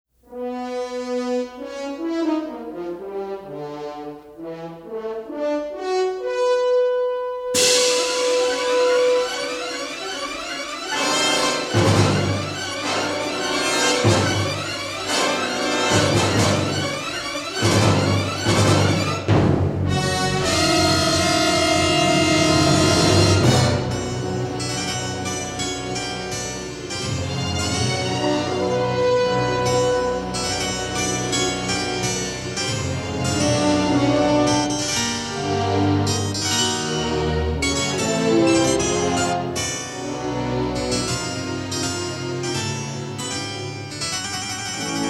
with notable use of harpsichord for sinister effect.
a soaring love theme